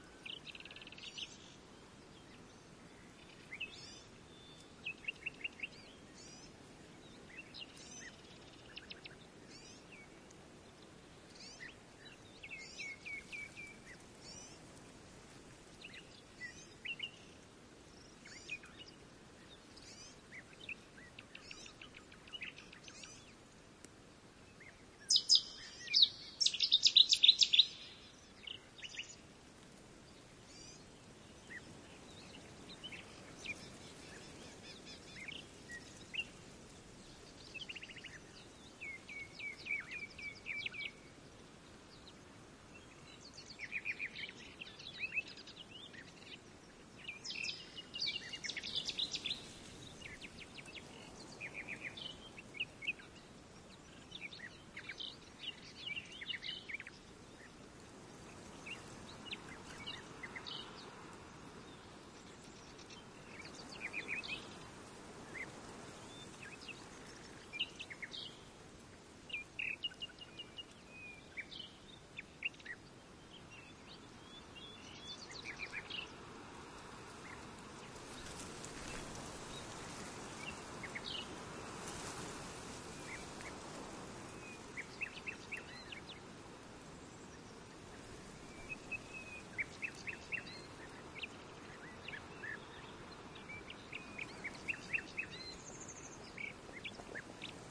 夜莺和其他鸟类在我的房间里录制，2000年5月。使用苹果麦克风和迷你光碟。
标签： 现场记录 性质 夜莺 春天
声道立体声